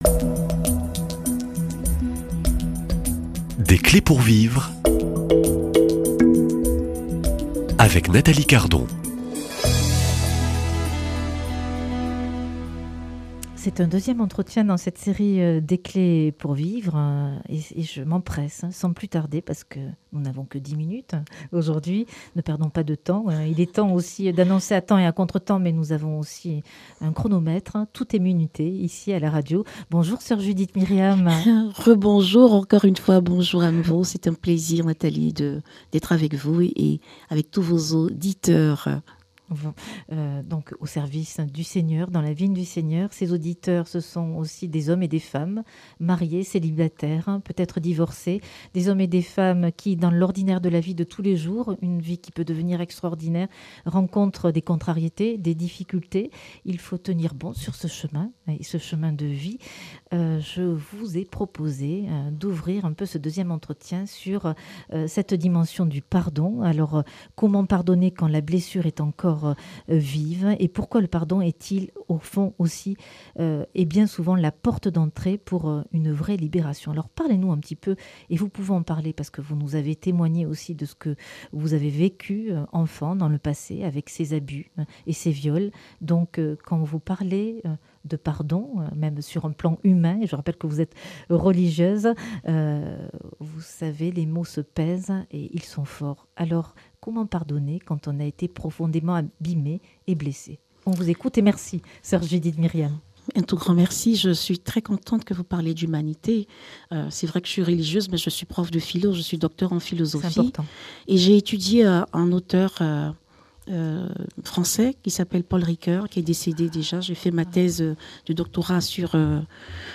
Comment le pardon, vécu avec le Christ, devient un chemin de libération intérieure. Entre foi, réconciliation et guérison, un échange vrai sur la grâce du pardon qui rend le cœur libre.